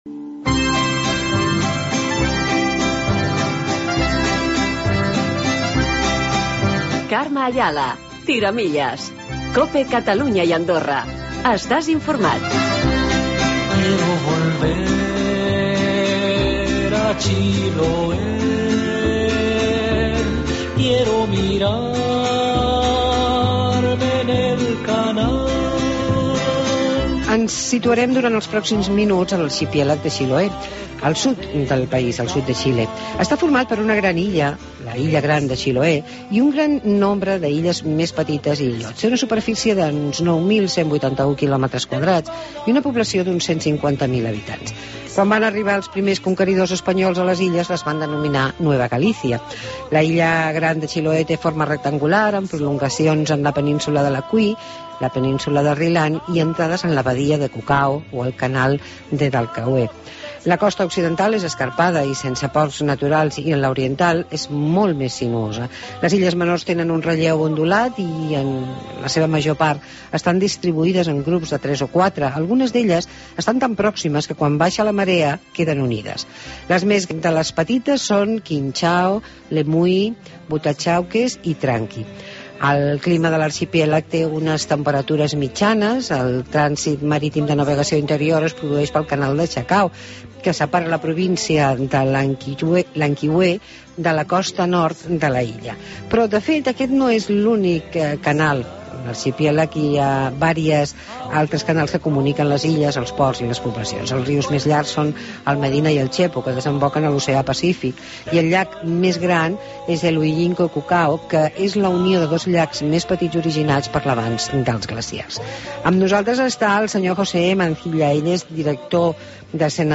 Entrevista con José Mancilla, director de Sernatur